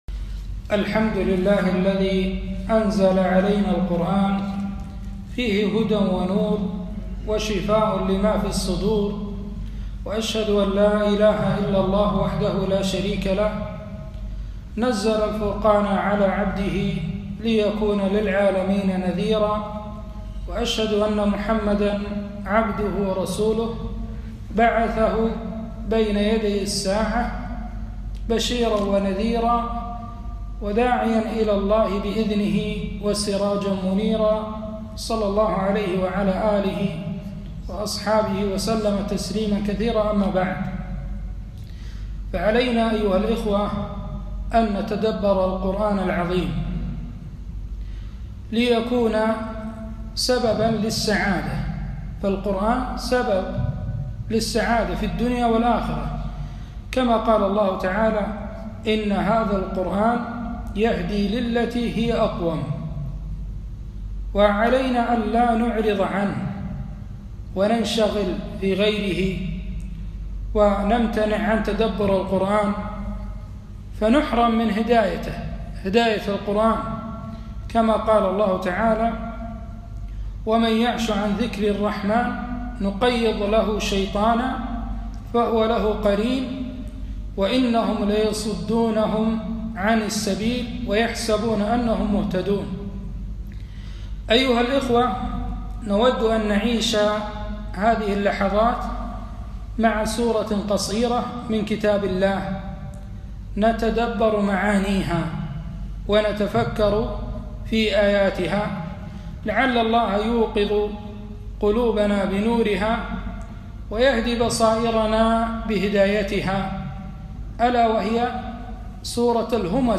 محاضرة - تأملات في سورة الهمزة